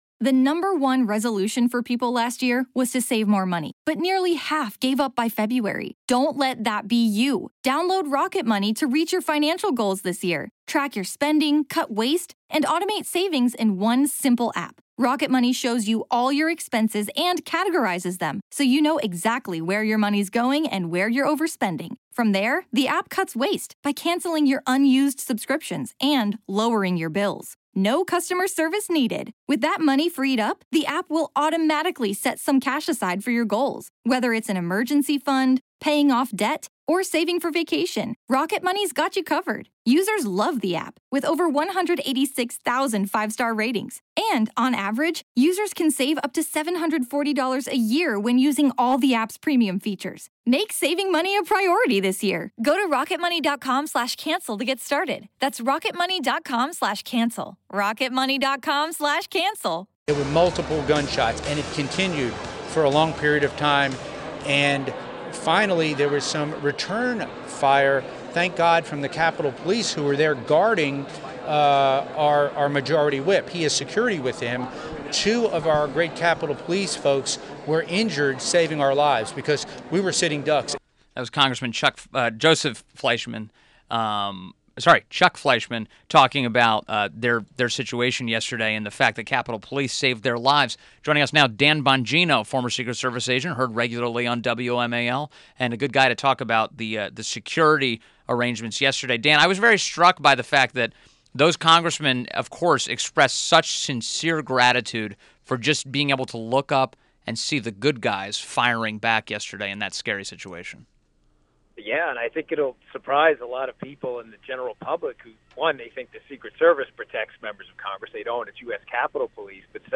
WMAL Interview - DAN BONGINO 06.15.17